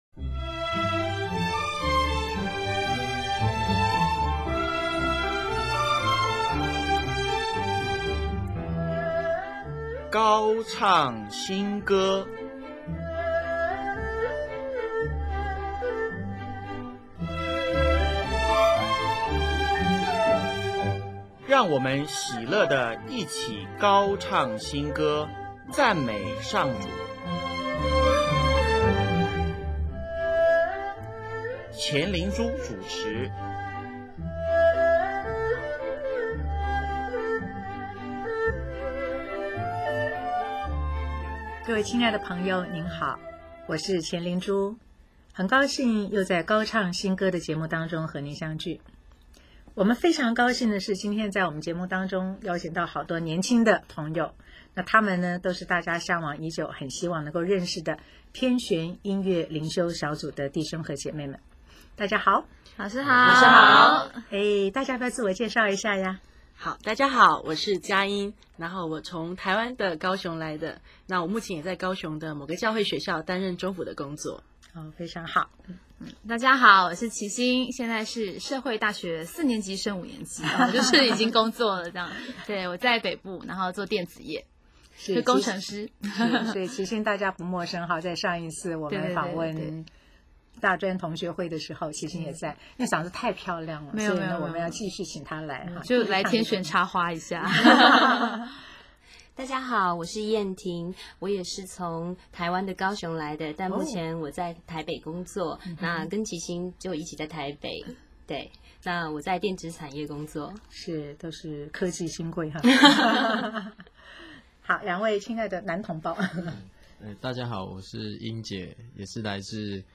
“喜欢”，简单而甜蜜的歌曲。